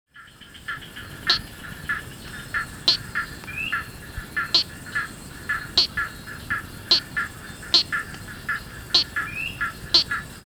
Play Especie: Gephyromantis moseri Género: Gephyromantis Familia: Mantellidae Órden: Anura Clase: Amphibia Título: The calls of the frogs of Madagascar.
Localidad: Madagascar: localidad tipo cerca de Andasibe Observaciones: De fondo pueden escucharse llamadas de Gephyromantis cornutus y Platypelis tuberifera Tipo de vocalización: Llamada
7 Gephyromantis moseri.mp3